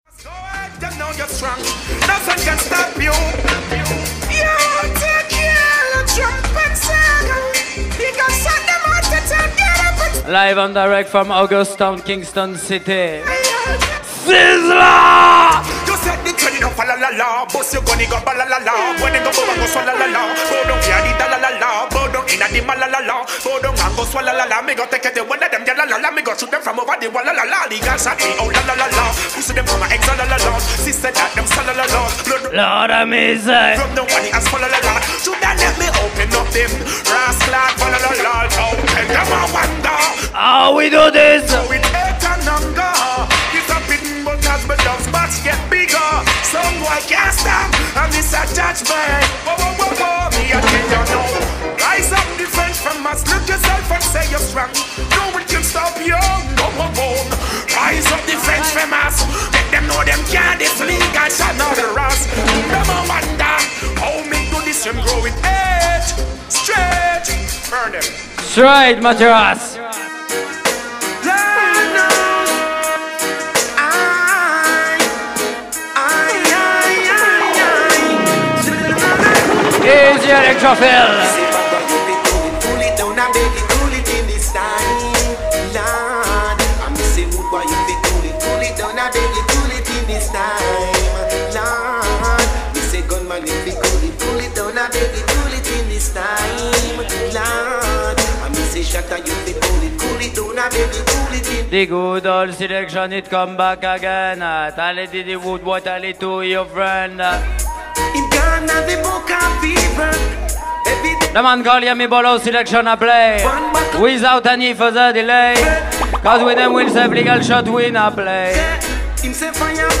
Big Night in Rennes City